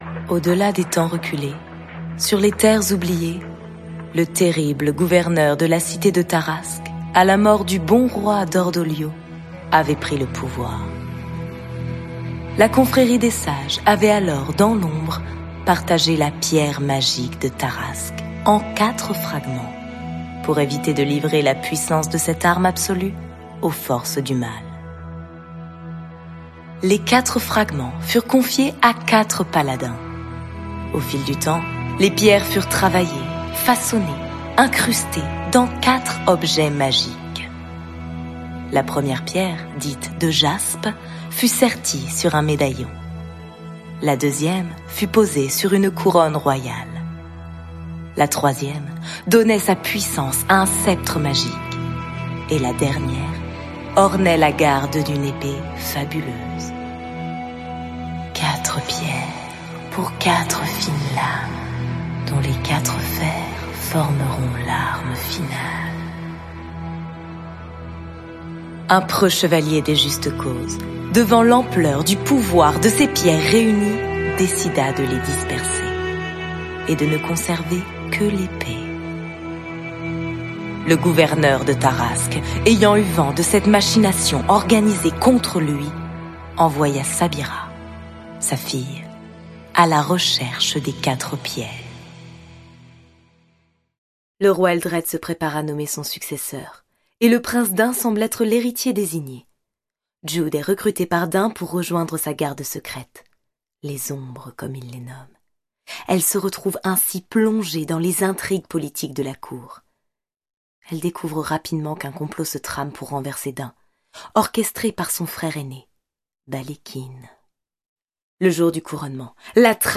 Bandes-son
Voix off
- Mezzo-soprano Soprano